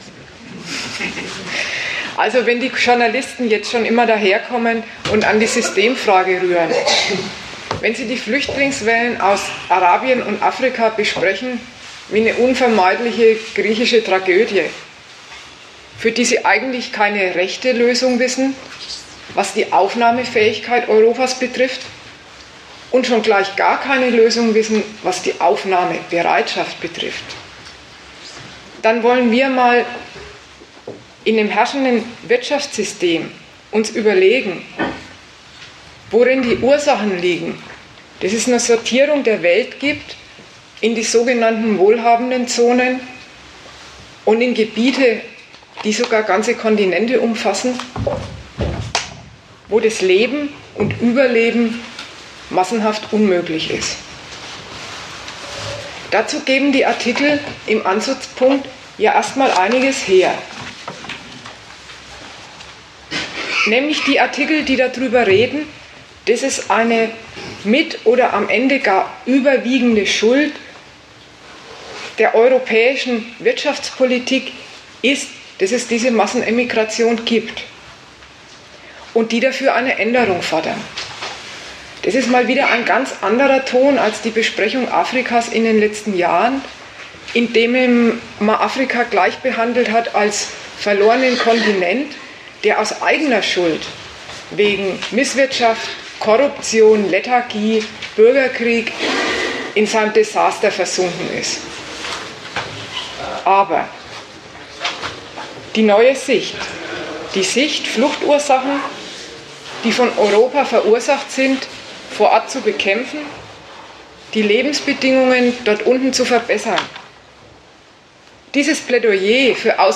Gastreferenten der Zeitschrift GegenStandpunkt